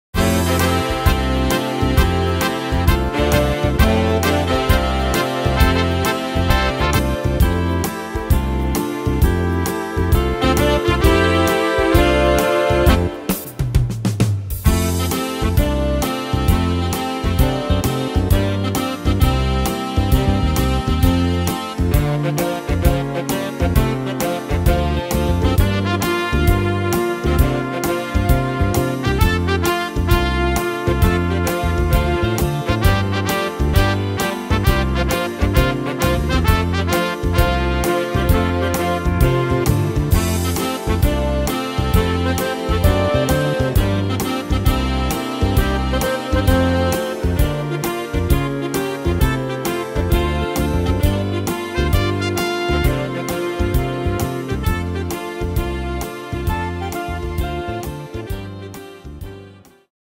Tempo: 132 / Tonart: F-Dur